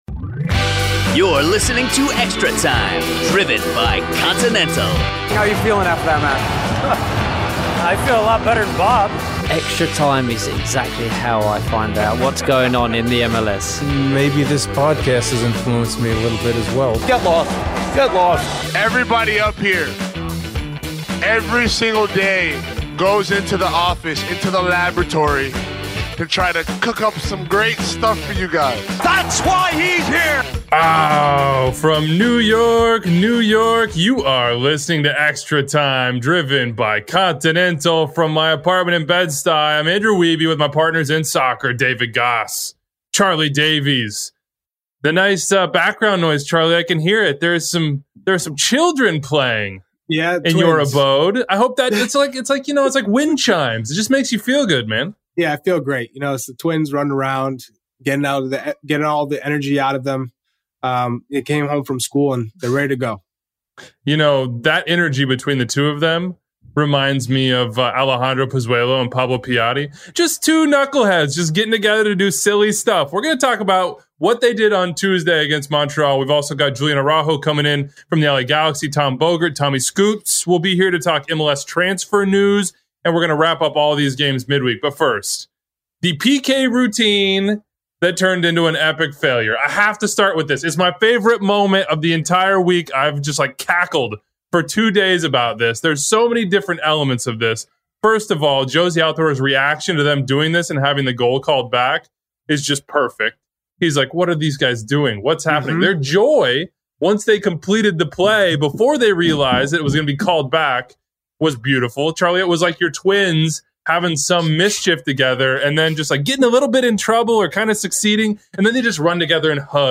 plus the LA Galaxy’s Julian Araujo calls in to explain what’s going on with the Galaxy and his international future.